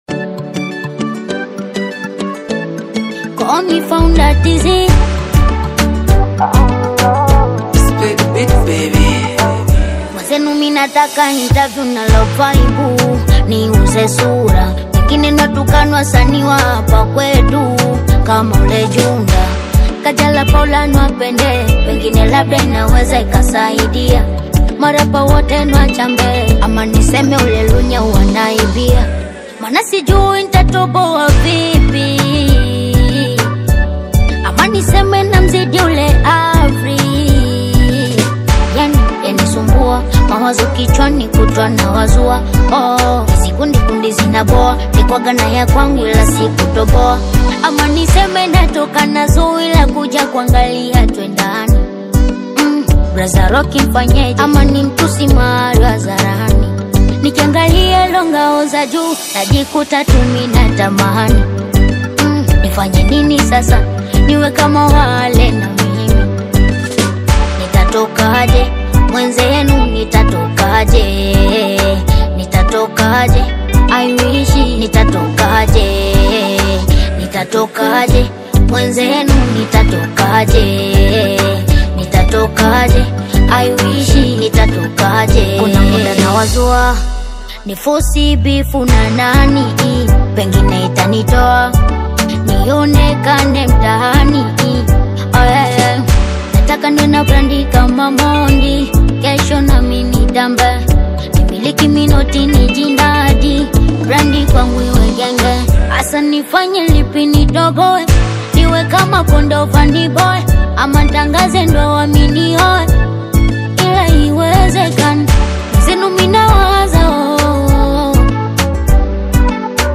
bongo music